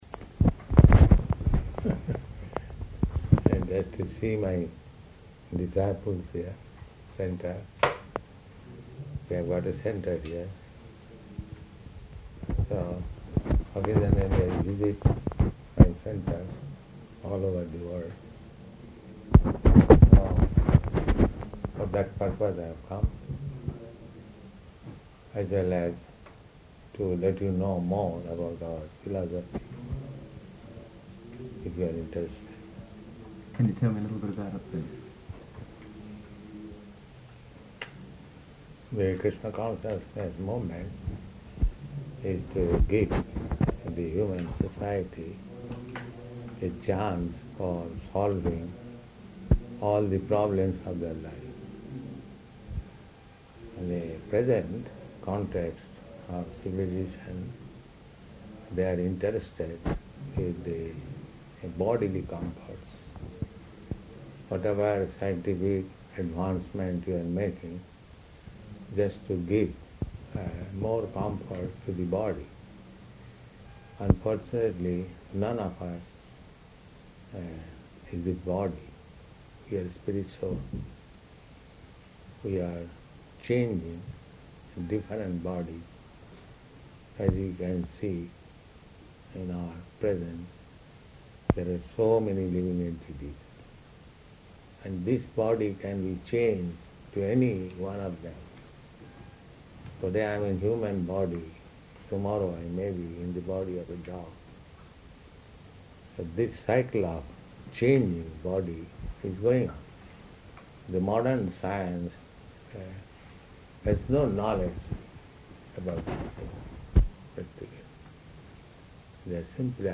Interview
Interview --:-- --:-- Type: Interview Dated: May 16th 1971 Location: Sydney Audio file: 710516IV-SYDNEY.mp3 Prabhupāda : ...we...